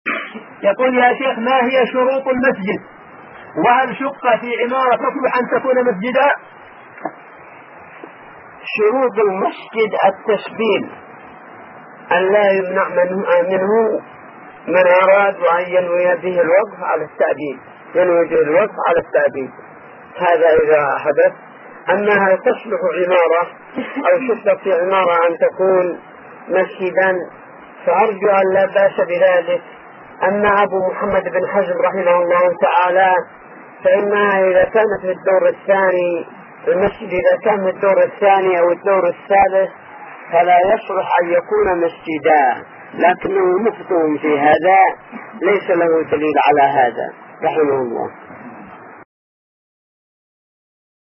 -------------- * كلمة غير مفهومة .